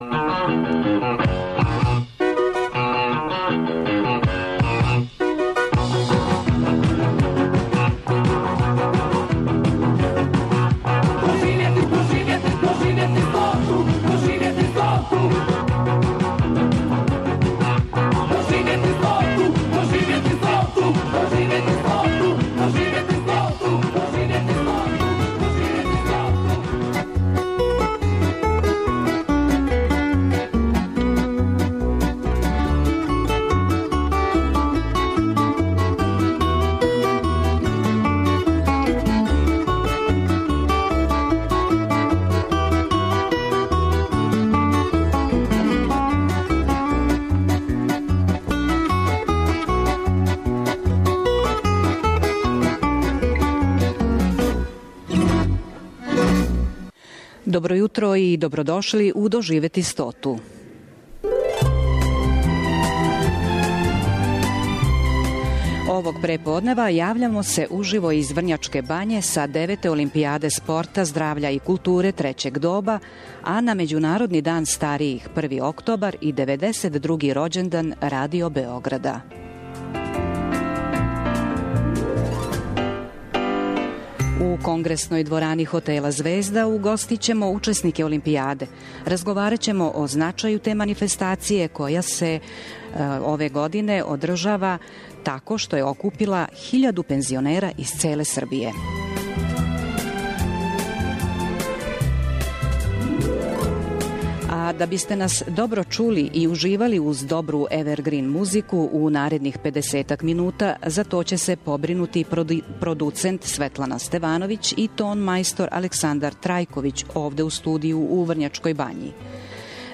Међународни дан старијих - 1. октобар и 92. рођендан Радио Београда, обележићемо свечано и радно у Врњачкој Бањи. Микрофони наше емисије биће инсталирани на Деветој олимпијади спорта, здравља и културе трећег доба одакле ћемо уживо пренети део атмосфере са те манифестације.